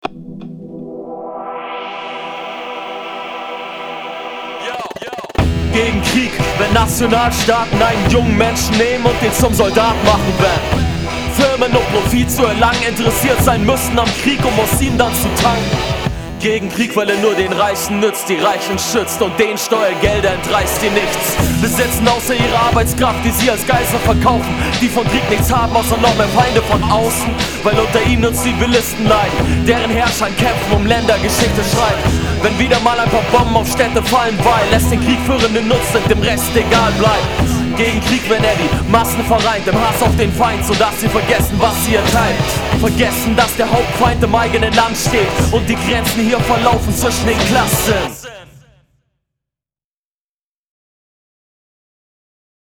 Noch 'n Gedicht